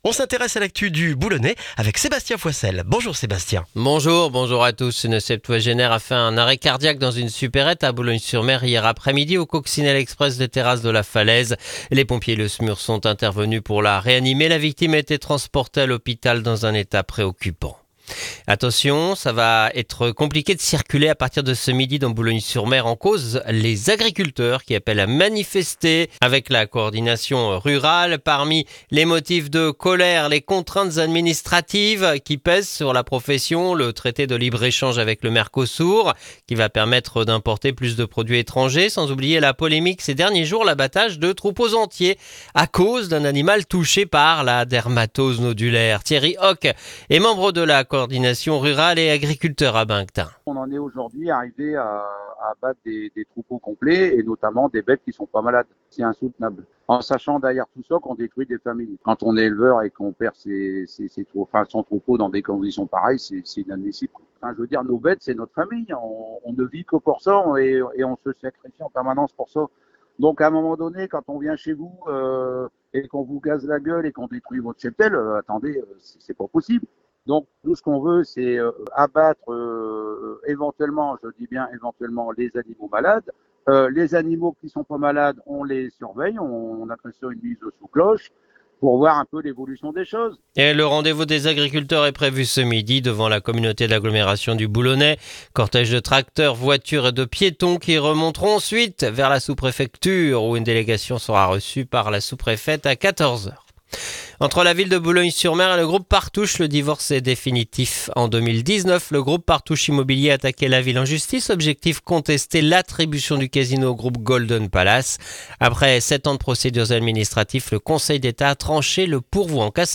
Le journal du lundi 15 décembre dans le boulonnais